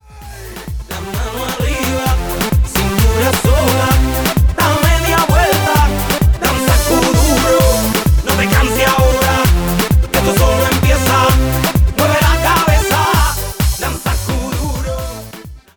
Из какого фильма данный саундтрек?